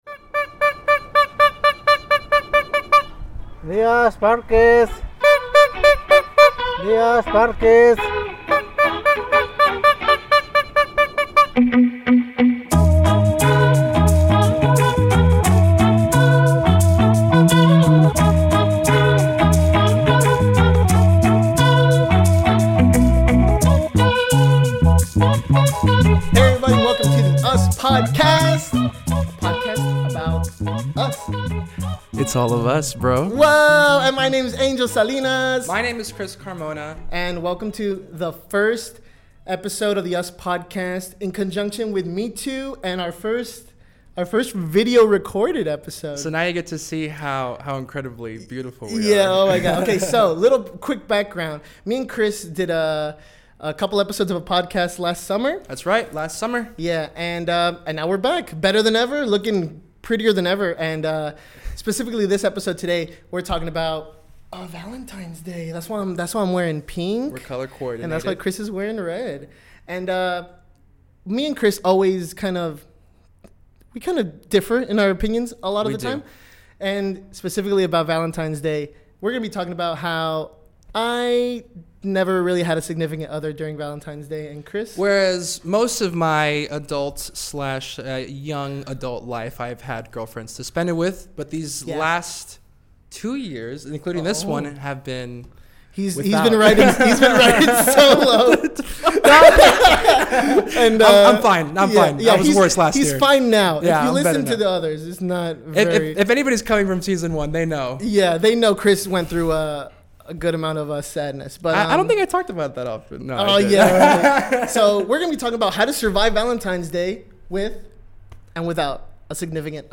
This audio is pulled from a special visual episode